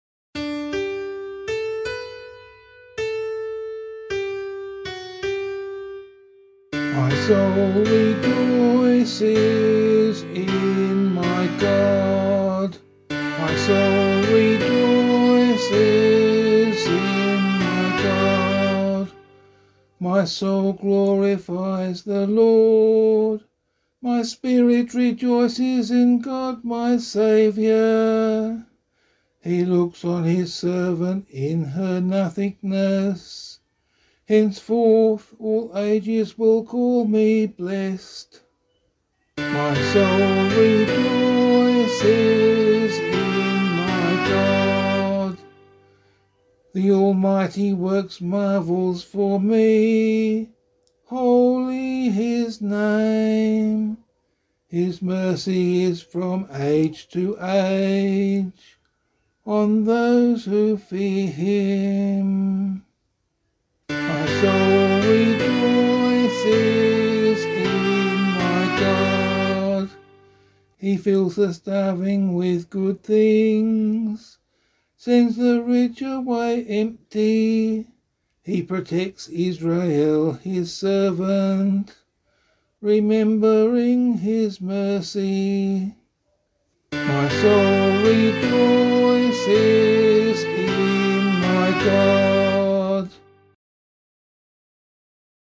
003 Advent 3 Psalm B [LiturgyShare 7 - Oz] - vocal.mp3